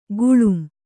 ♪ guḷum